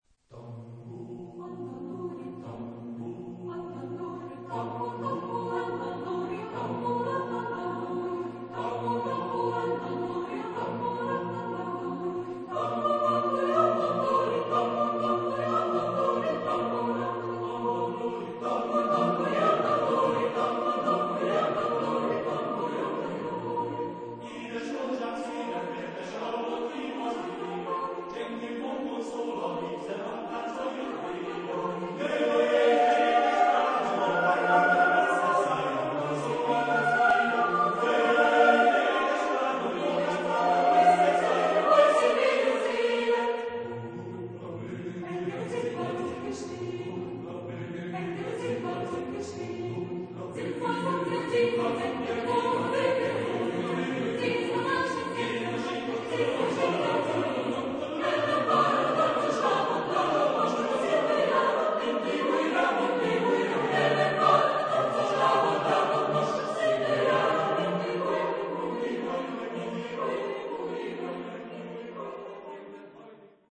Genre-Style-Form: Secular ; Folk dance
Mood of the piece: fast ; dancing ; happy
Type of Choir: SATB  (4 mixed voices )
Tonality: B flat major